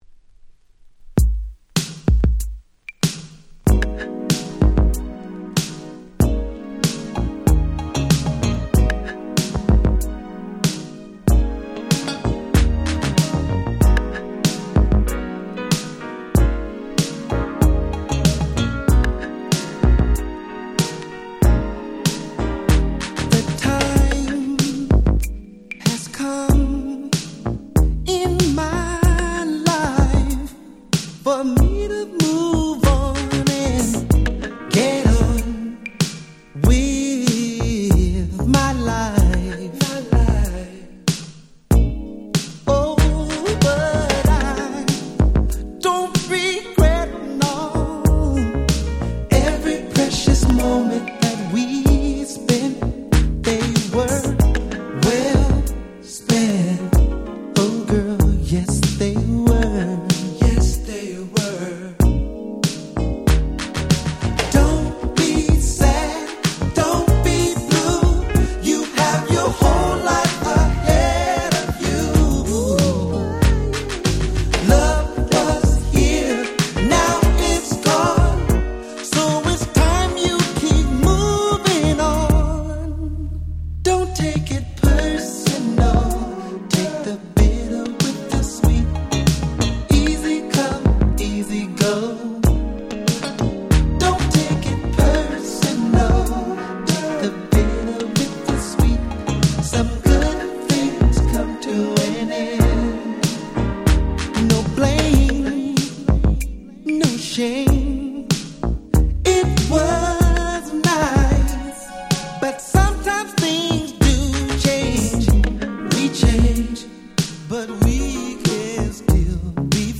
89' Smash Hit R&B !!
ブラコンスーパークラシック！！
MellowGroovy!!